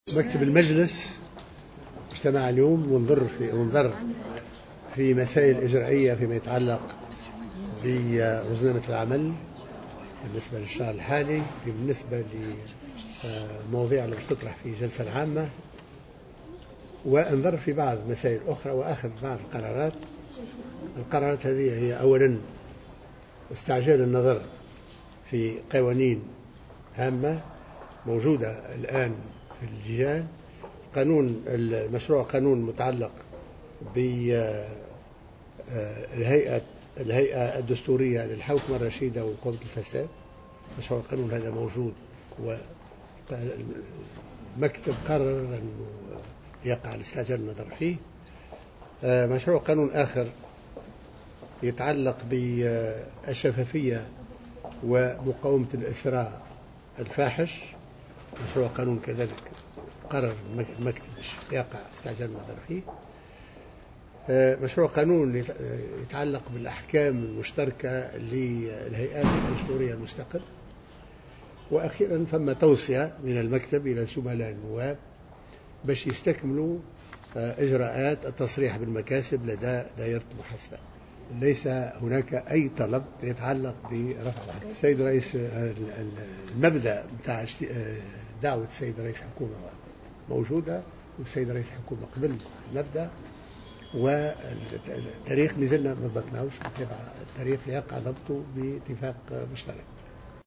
نفى رئيس مجلس نواب الشعب محمد الناصر، في تصريح لمراسل الجوهرة اف ام اثر اجتماع مكتب المجلس، تلقي المكتب مطالب من نواب لرفع الحصانة عنهم.